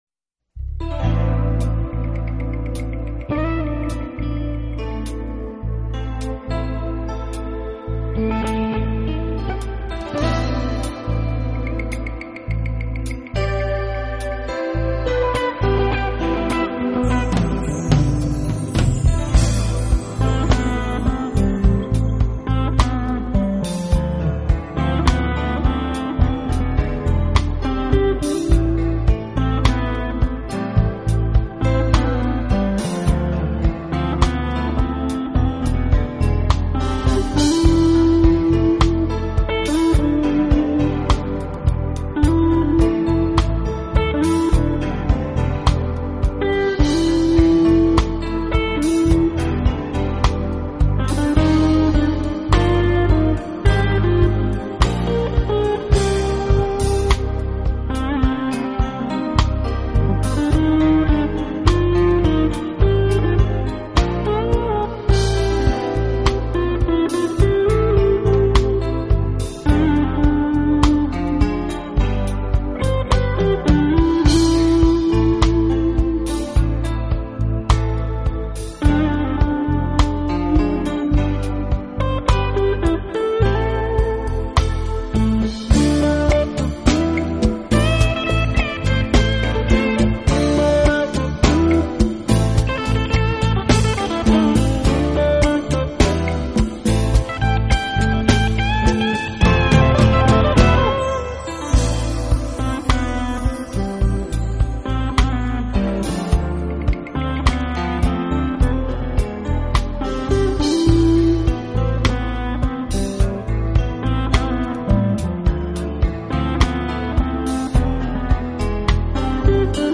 特企经典双盘一次拥有：金曲盘重温拉丁摇滚魅力，演奏盘亲炙吉他大师神技